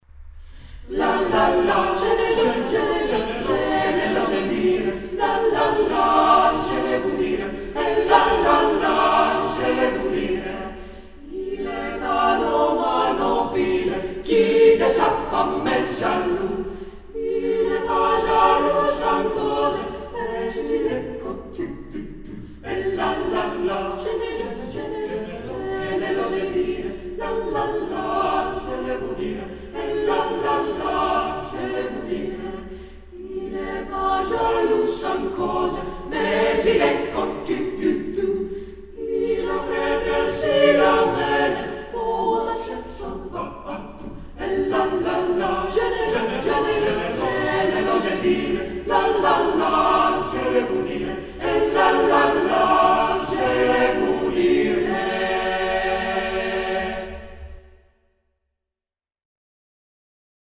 Ηχογράφηση από την πρόβα της Τρίτης 2 Μαΐου 2006.
Με ισοστάθμιση, αντήχηση και στερεοφωνία
eq = Equalizer, rev = reverb